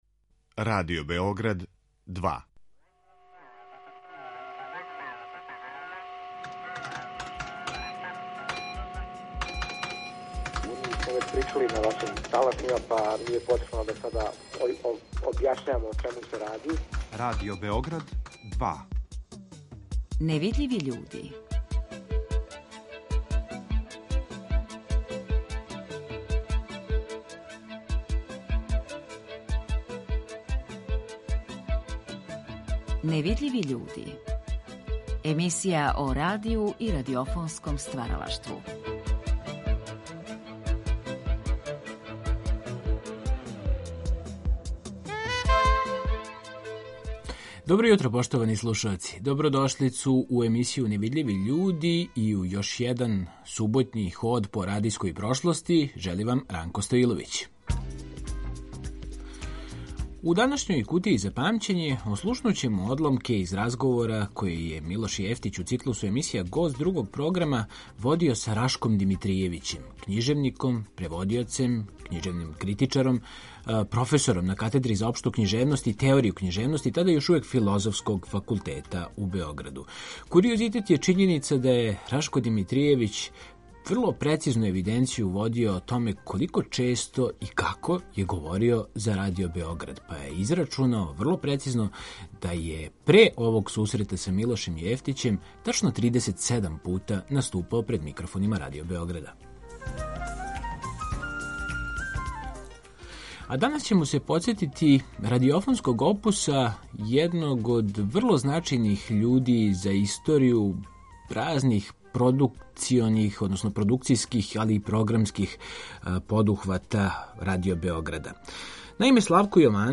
Емисија о радију и радиофонском стваралаштву